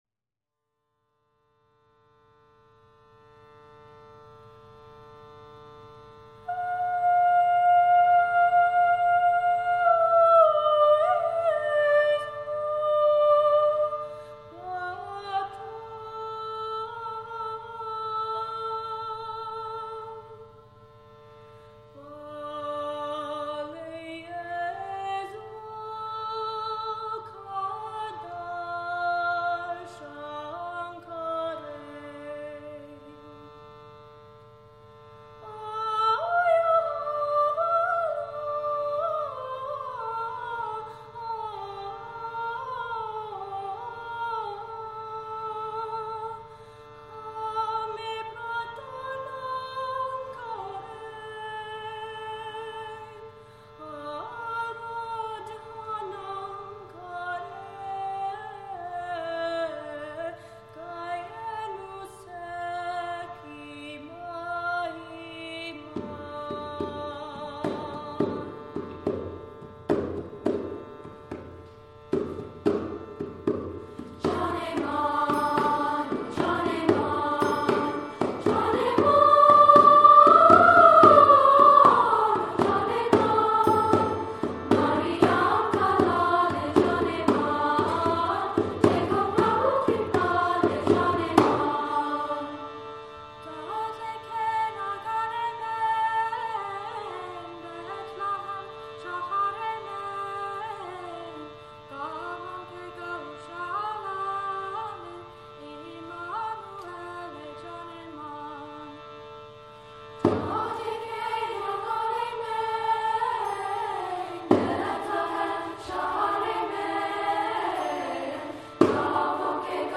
Composer: Goan carol
Voicing: SSA and Piano